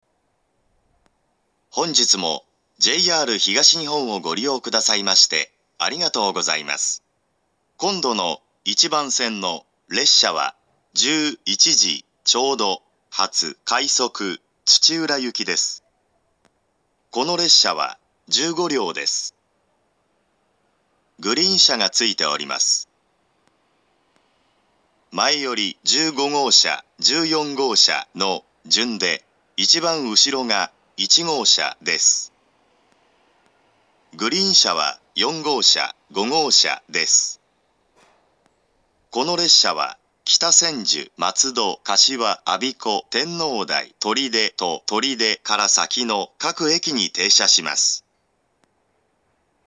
２０１４年１２月１５日には、２０１５年３月開業の上野東京ラインに対応するため、自動放送の男声が変更されています。
１番線到着予告放送